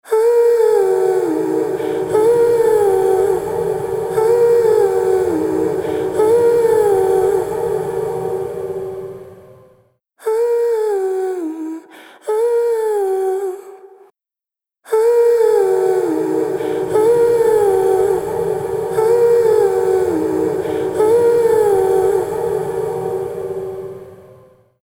ピッチシフティングを融合した、幻想的なリバーブ
ShimmerVerb | Vocals | Preset: Shores
ShimmerVerb-Eventide-Vocals-Shores.mp3